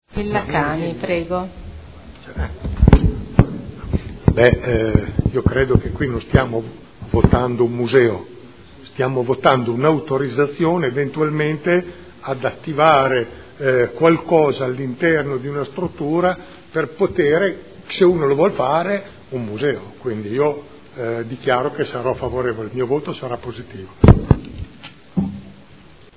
Seduta del 31 marzo. Proposta di deliberazione: Proposta di progetto - Casa Museo del Maestro Luciano Pavarotti – Stradello Nava – Z.E. 2400 – Nulla osta in deroga agli strumenti urbanistici comunali – Art. 20 L.R. 15/2013. Dichiarazioni di voto